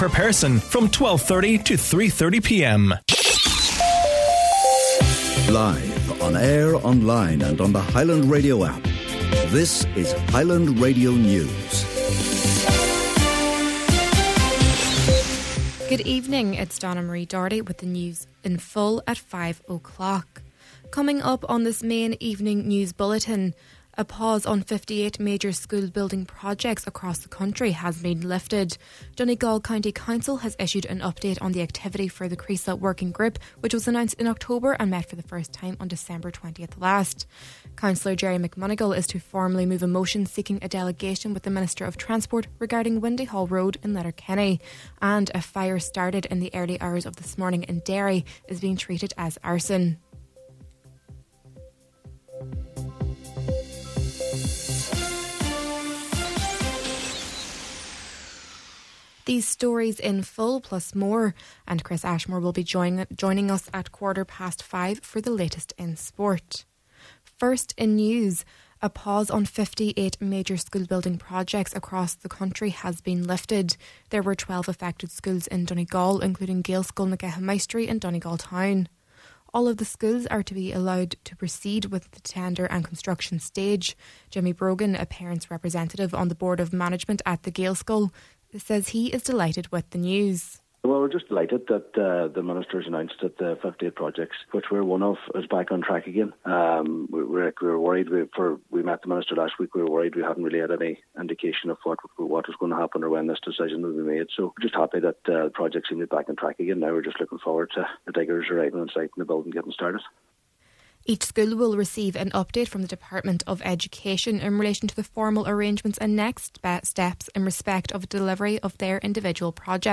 News, sport and obituary notices, Wednesday, April 5th